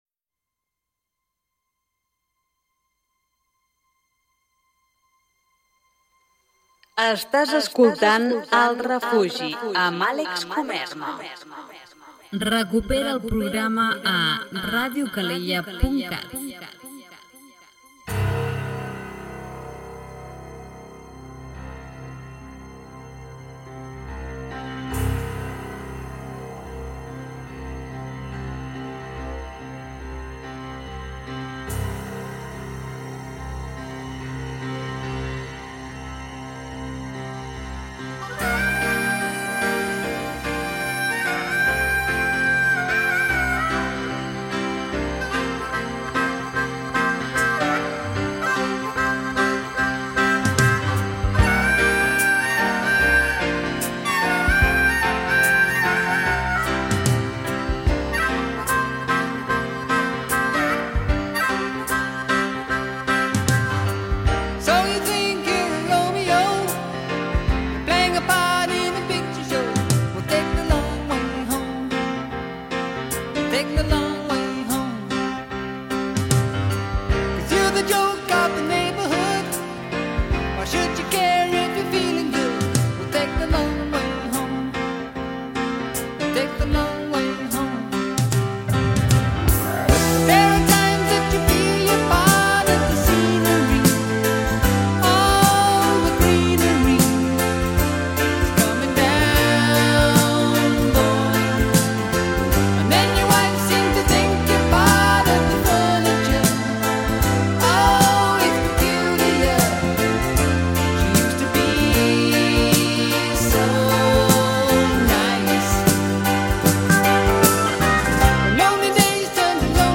rock i rock progressiu